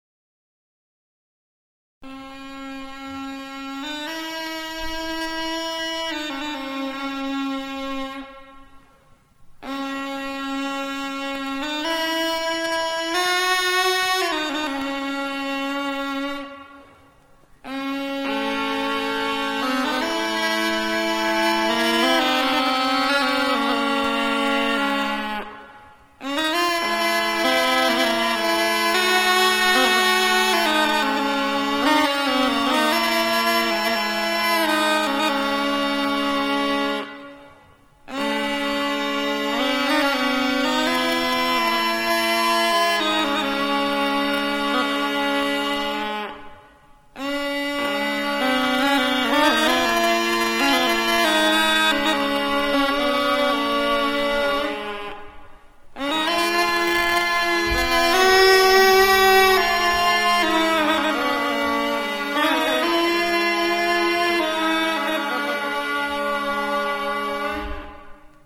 birbyne made of straw
siaudo_birbyne.mp3